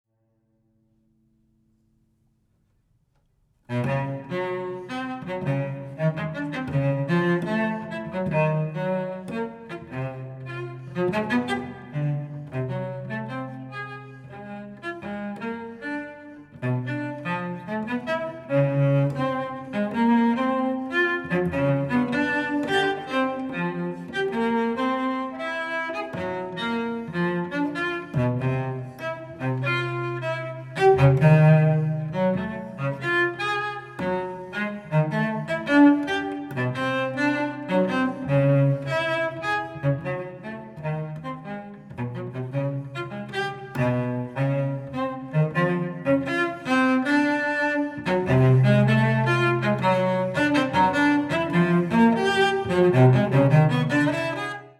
An example is when the boy is riding on his enlarged ant, the ride is bumpy, but you cannot avoid it with so many feet. It is characterized by the uneven time signatures and the large intervals: